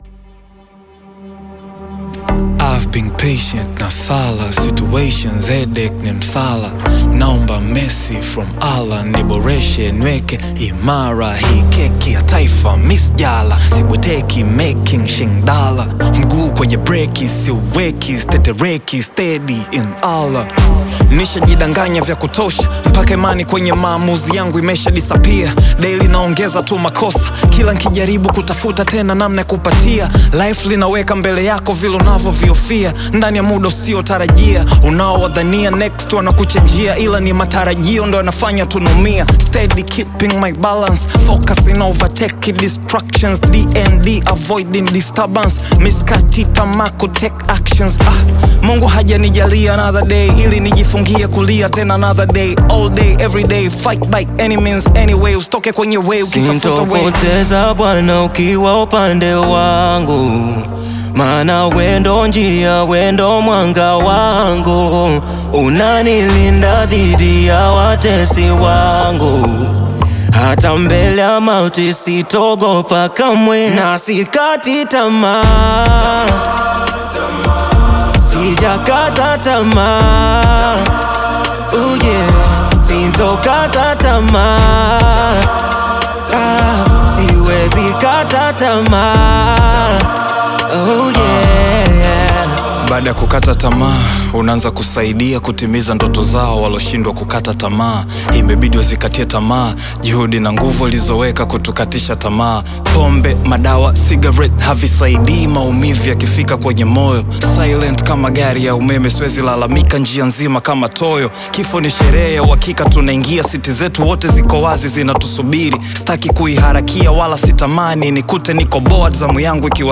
atmospheric soundscapes